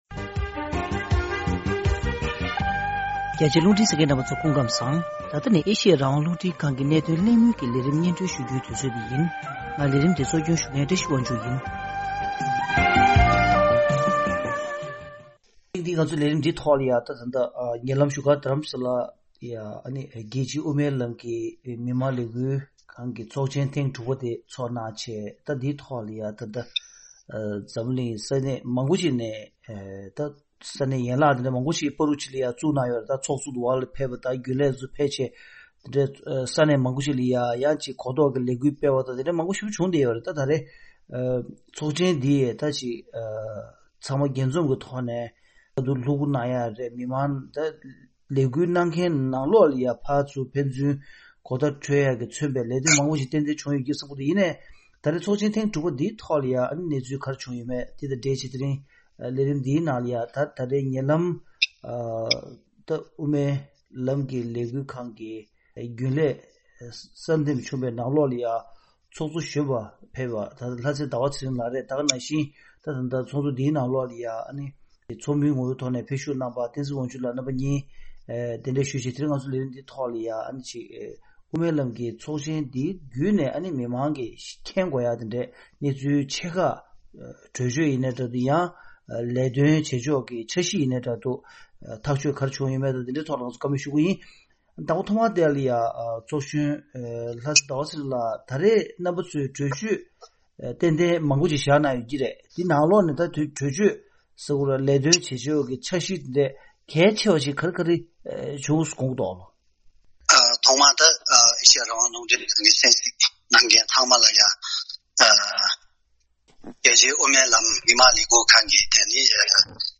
རྒྱལ་སྤྱིའི་དབུ་མའི་ལམ་གྱི་ལས་འགུལ་ཁང་གི་སྐབས་༥པའི་ཚོགས་ཆེན་ཐོག་གཏན་འབེབས་གནང་པའི་གྲོས་ཆོད་ཁག་དང་མ་འོངས་ལས་དོན་གནང་ཕྱོགས་ཐད་གླེང་མོལ།